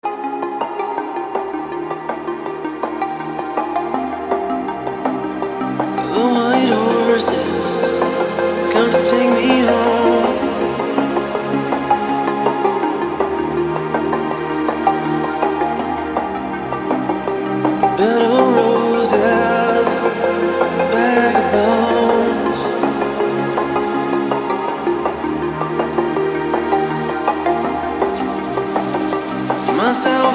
• Качество: 160, Stereo
мужской вокал
dance
club
drum&bass
vocal